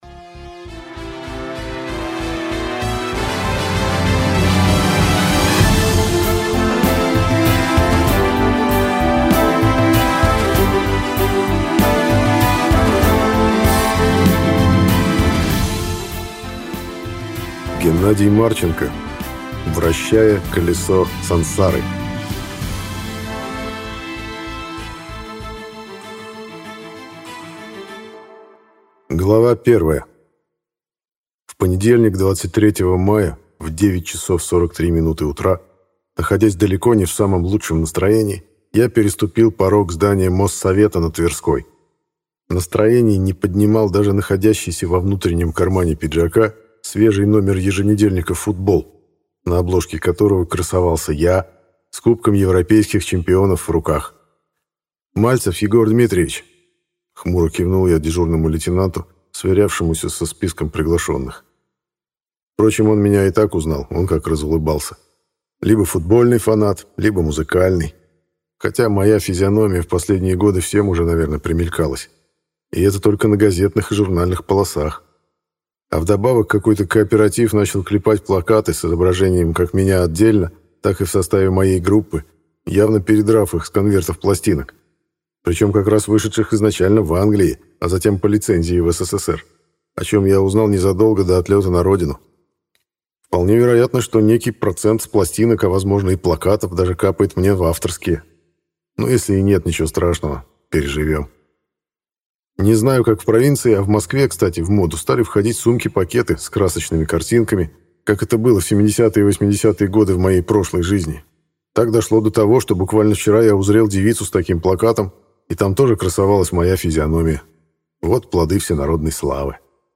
Аудиокнига Вращая колесо Сансары | Библиотека аудиокниг